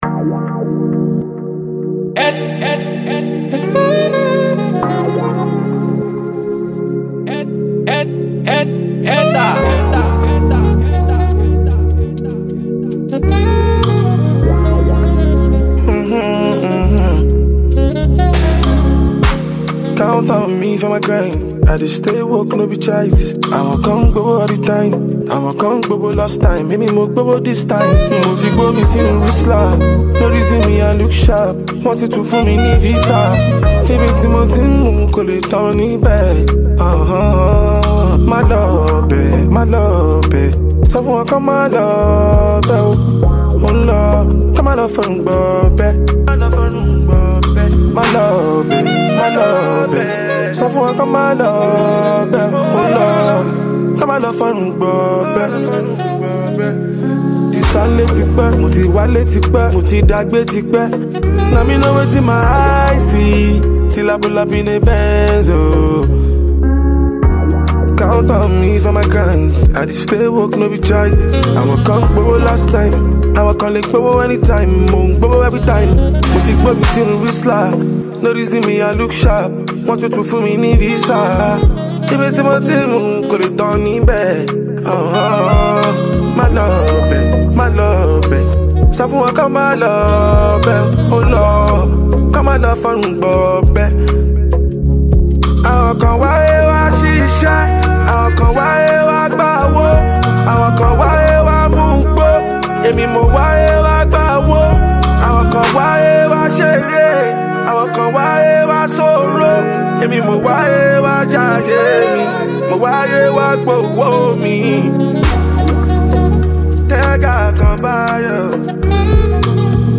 Nigerian rap phenomenon, singer, and songwriter